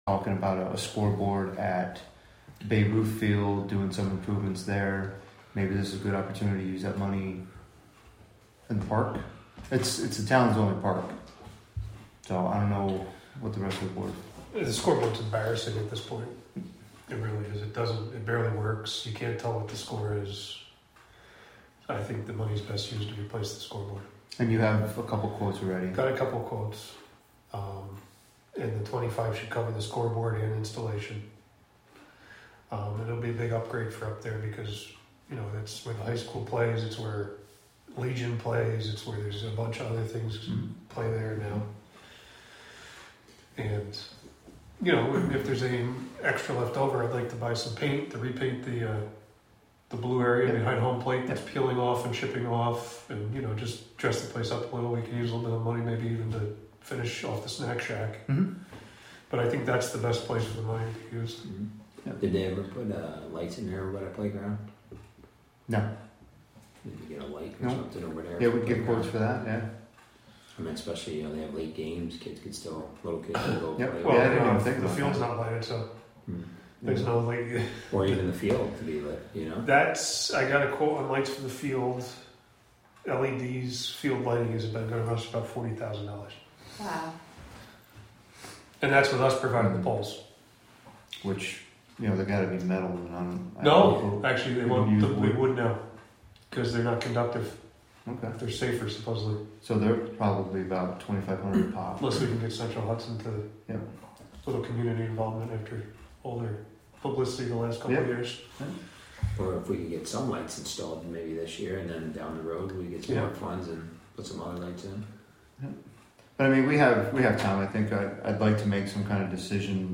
Live from the Town of Catskill: May 15, 2024 Catskill Town Board Meeting (Audio)